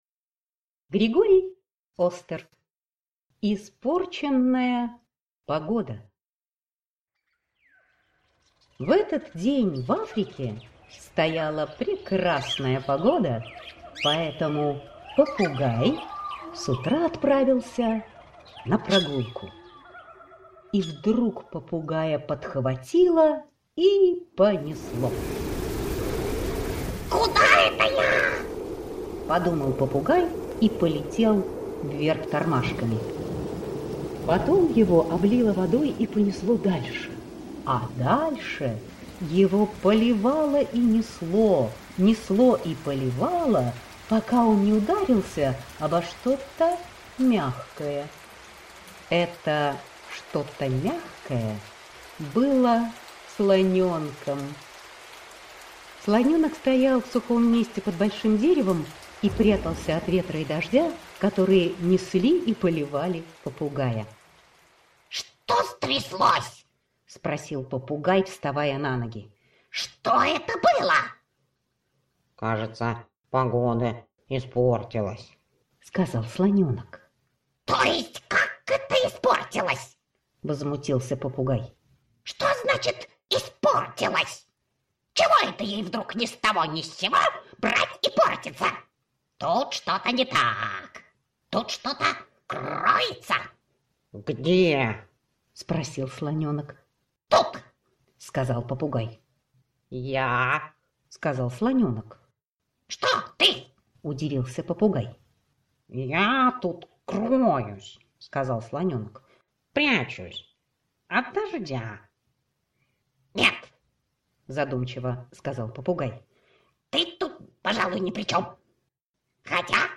Испорченная погода - аудиосказка Григория Остера - слушать онлайн